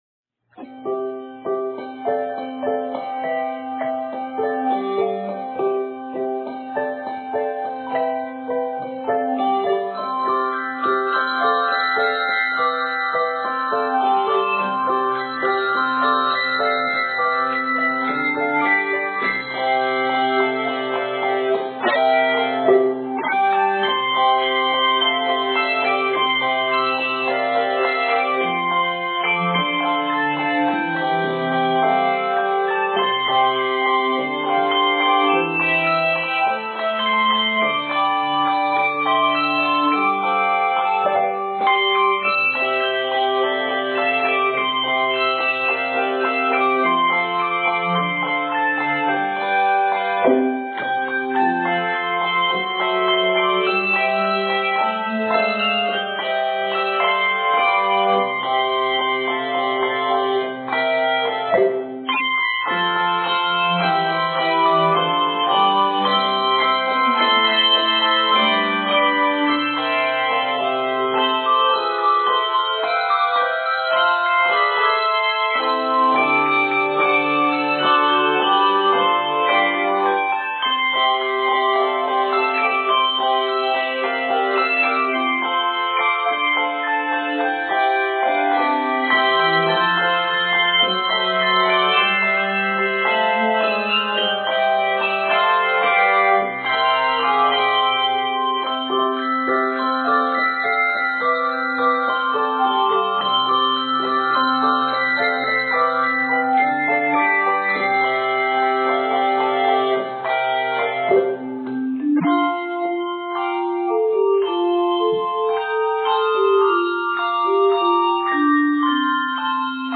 Set in the keys of C Major and F Major, measures total 82.